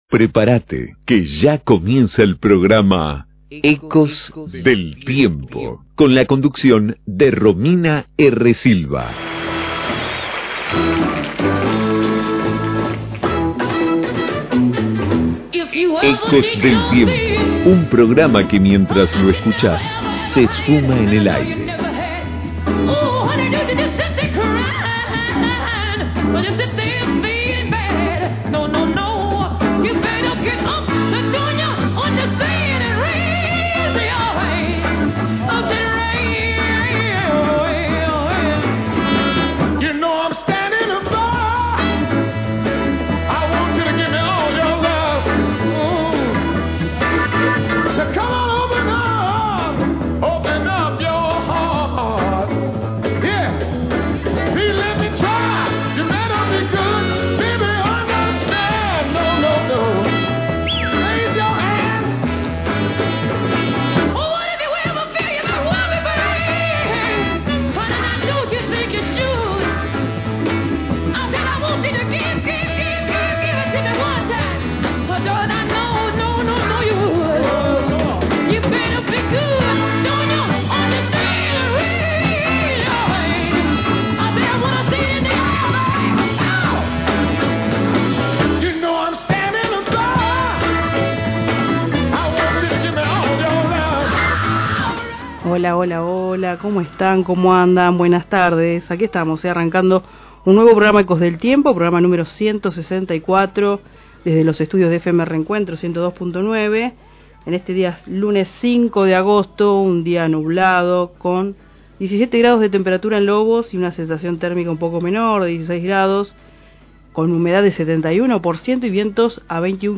Noticias de actualidad